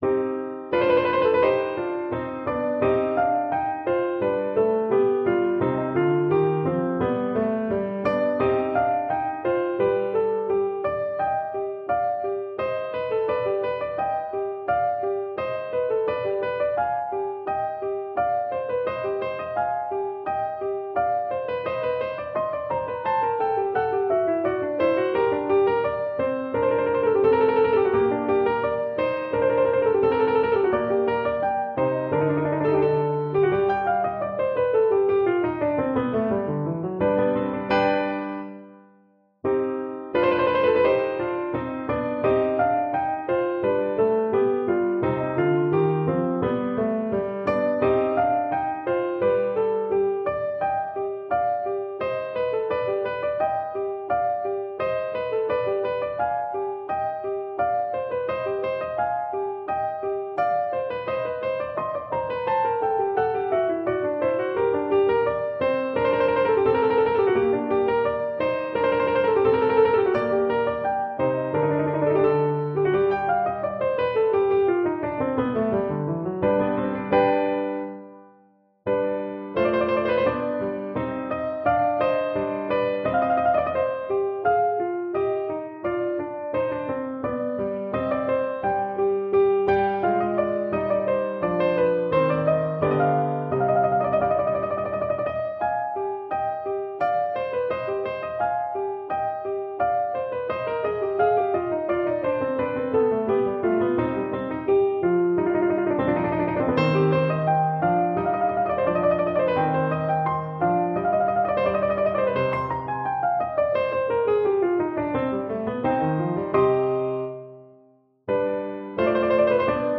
It is simple yet beautiful and rustic.
• Duet (Violin / Viola)